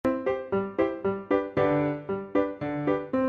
SFX我的世界欢乐音效下载
SFX音效